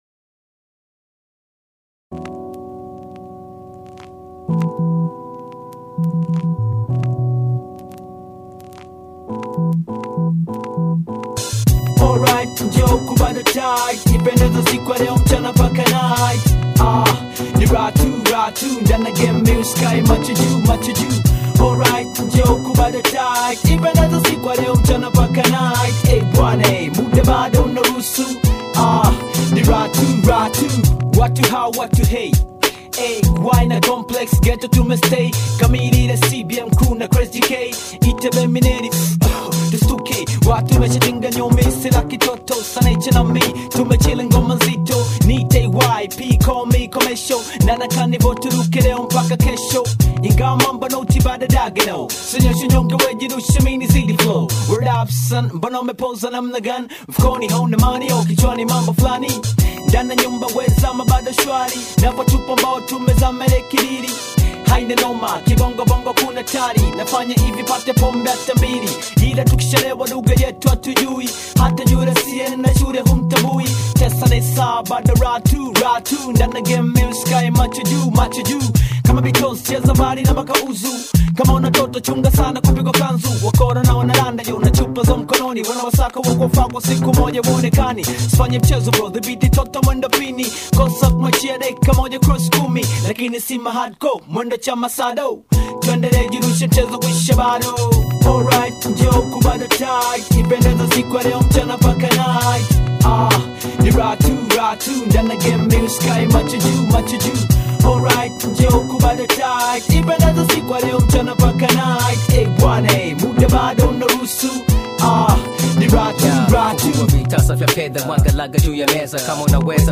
energetic musical journey